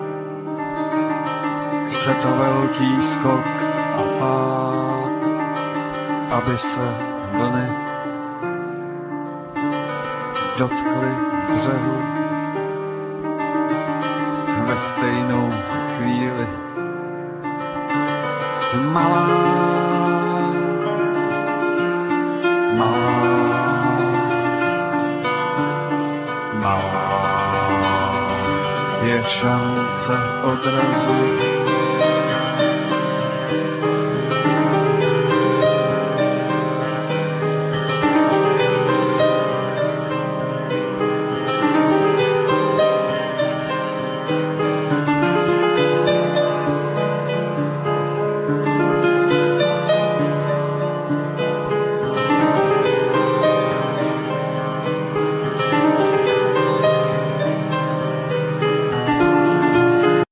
Vocal,Piano
Drums
Bass